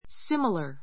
similar 中 A2 símələ r スィ ミら 形容詞 同じような , 似ている similar dresses similar dresses 同じような洋服 be similar to ～ be similar to ～ ～に似ている, ～と同様だ Your blouse is similar to mine, and our scarfs are alike too.